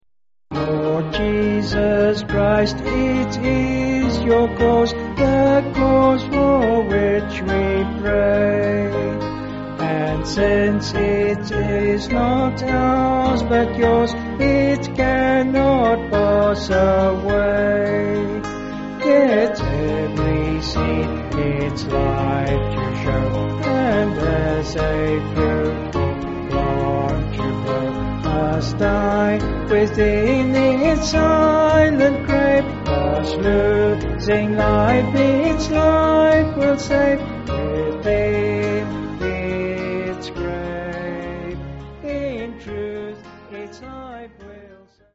(BH)   3/Ab
Vocals and Band